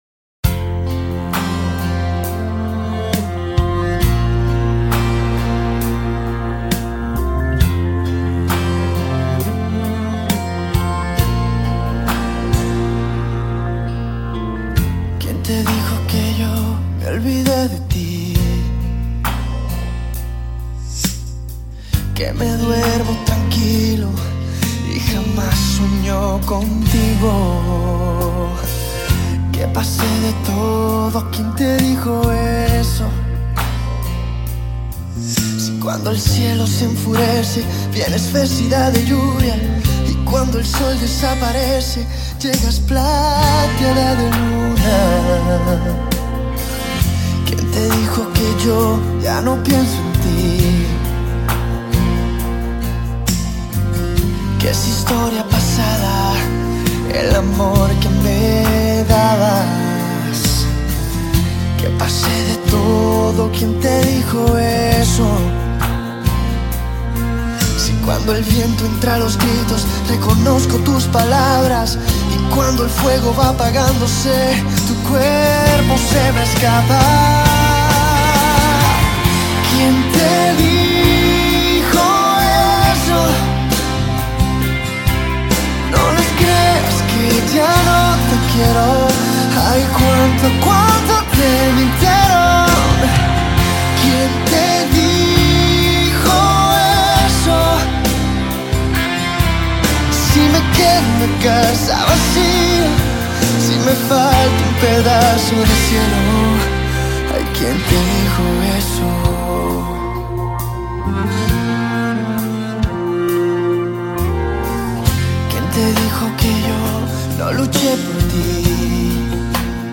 下面是我精选的十首经典西班牙语歌曲，曲风多为慢板抒情，希望大家喜欢。
他的声音高亢而充满力量,浑厚中透漏着柔情,所以慢版情歌是他的拿手好戏，本专辑走流行摇滚路线，大受好评。